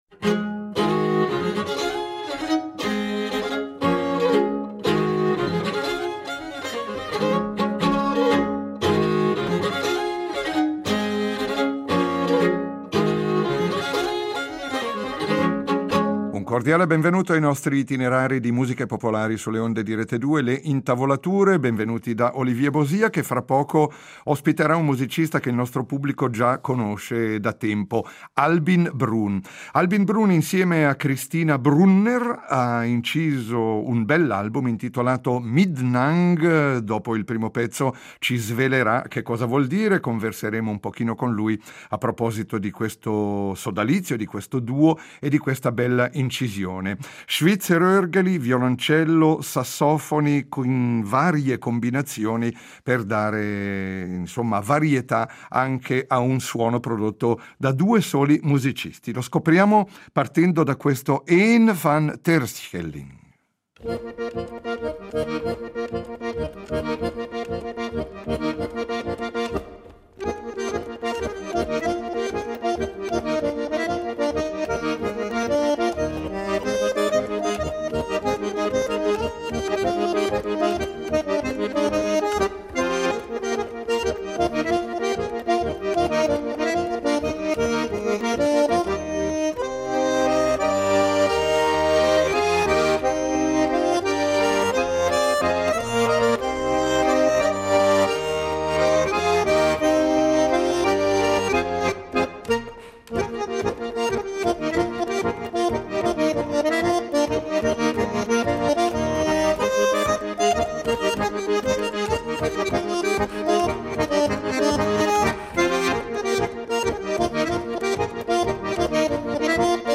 violoncellista